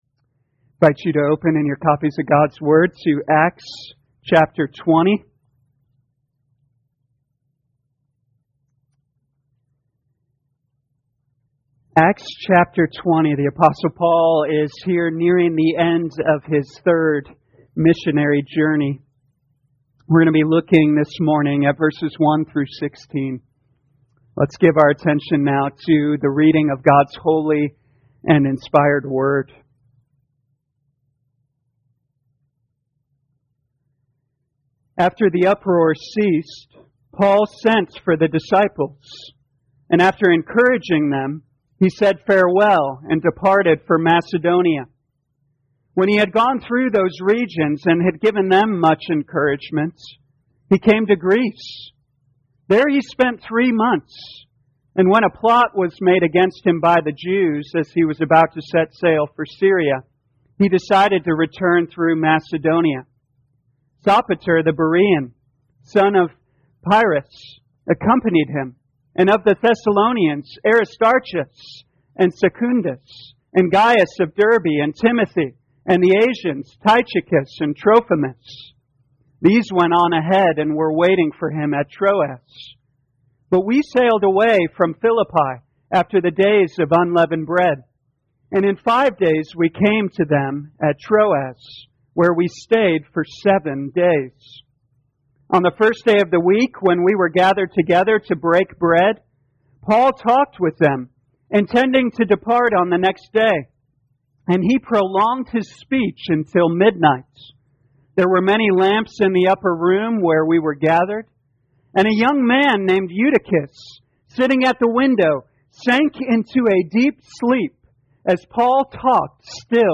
2022 Acts Morning Service Download: Audio All sermons are copyright by this church or the speaker indicated.